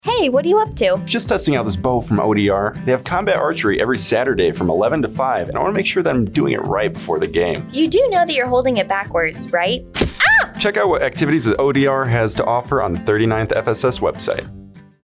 AFN INCIRLIK RADIO SPOT: Outdoor Recreation Combat Archery